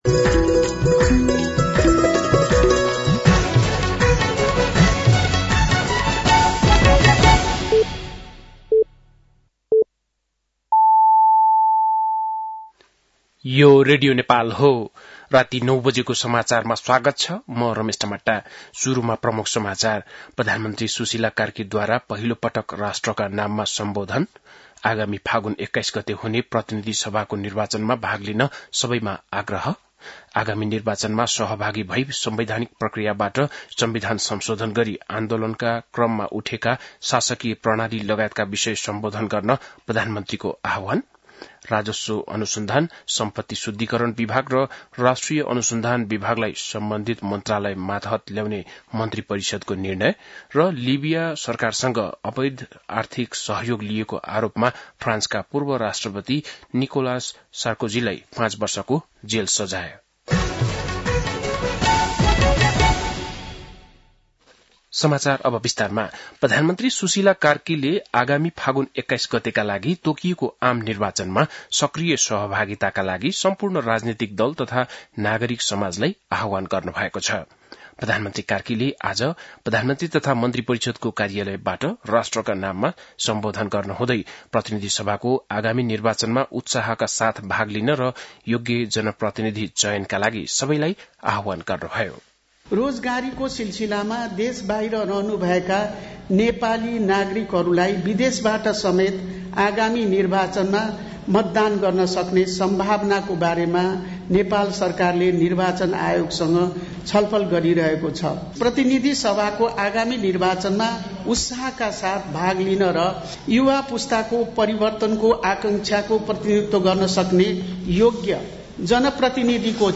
बेलुकी ९ बजेको नेपाली समाचार : ९ असोज , २०८२
9-PM-Nepali-NEWS-6-9.mp3